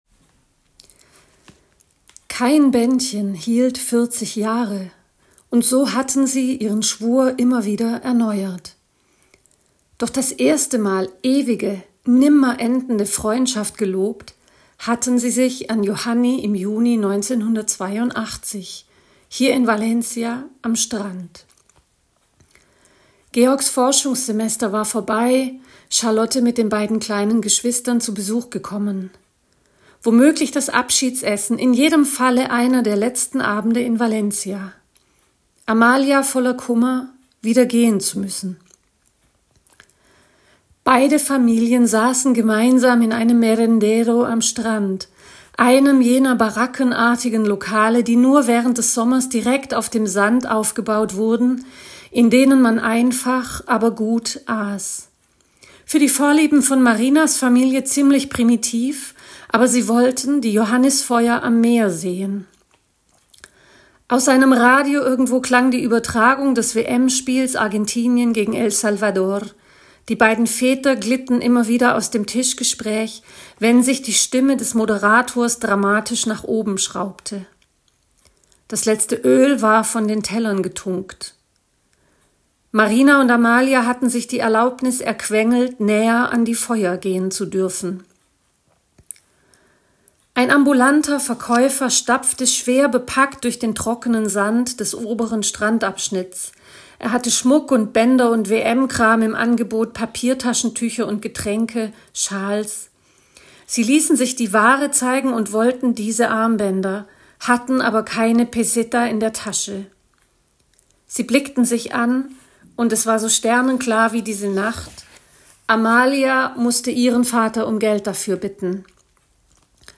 Ein Ausschnitt aus „Die Taucherin“ im Lit.Cast des Förderkreises der Schriftsteller in Baden-Württemberg.